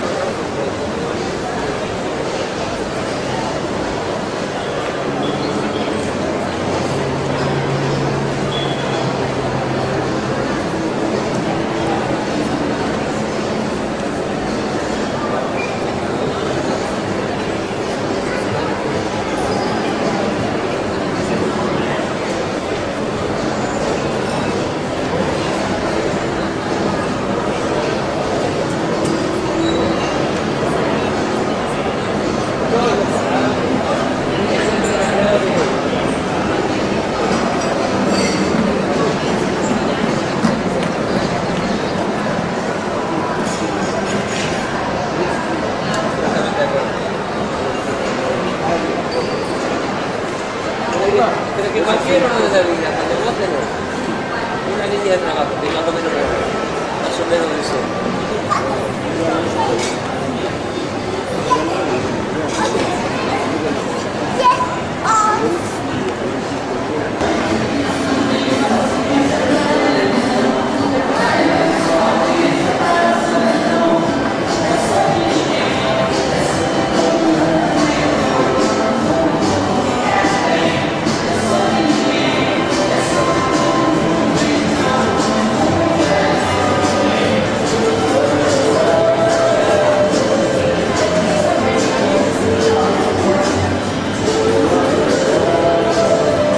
Family Crowds » Family crowds pt.1 dry
描述：Recorded with a Sharp 722 minidisc portable recorder using an Aiwa stereo clip on mic (model unknown). Four layers of recordings made at the Bronx Zoo in the Reptile House. I layered them in LIVE
标签： indoor crowds families kids murmur people children sfx
声道立体声